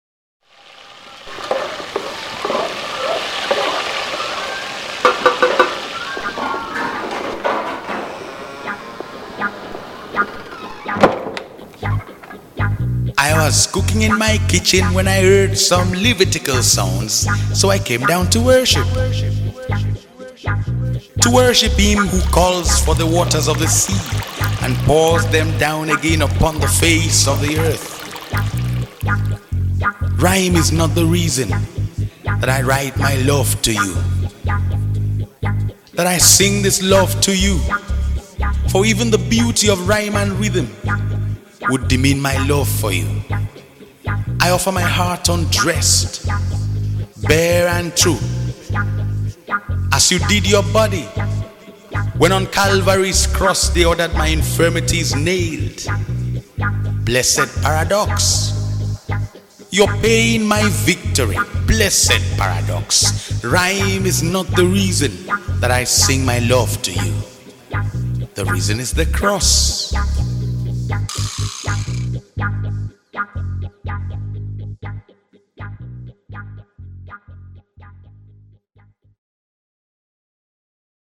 March 12, 2025 Publisher 01 Gospel 0